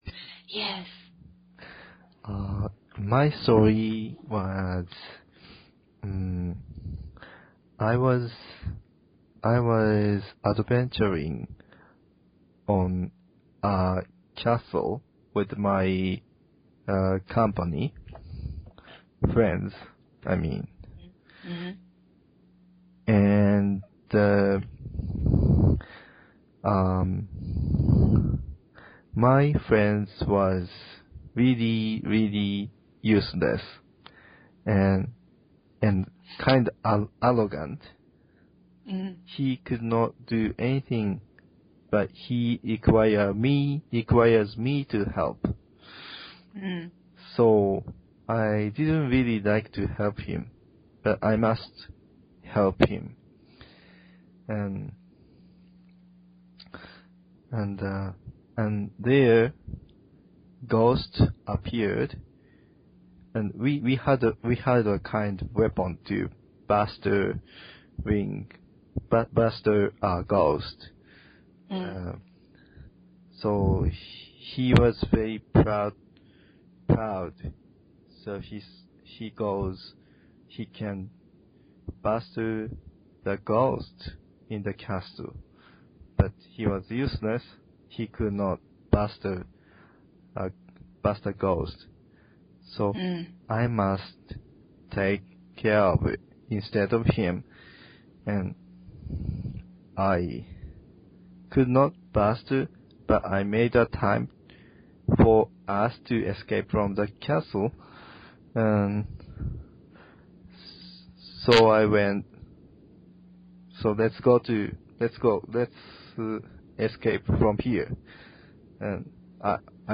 relaxed talk